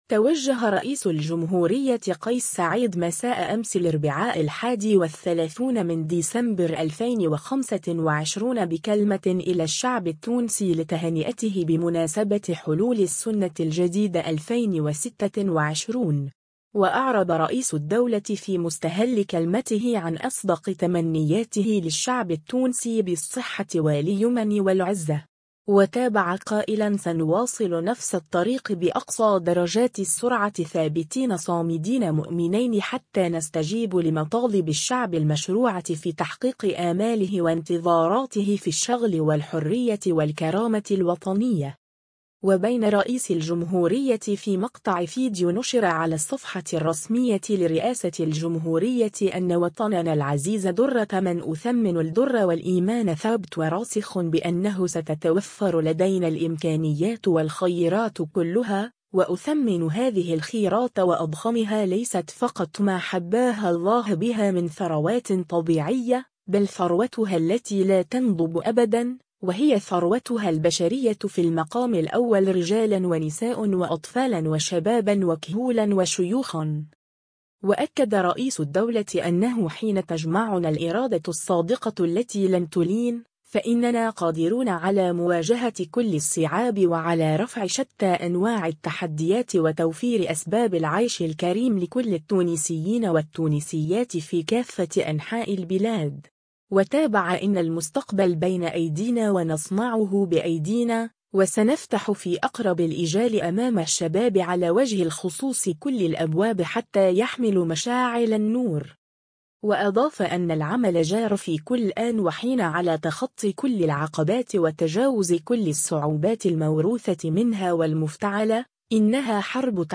توجه رئيس الجمهورية قيس سعيّد مساء أمس الاربعاء 31 ديسمبر 2025 بكلمة إلى الشعب التونسي لتهنئته بمناسبة حلول السّنة الجديدة 2026.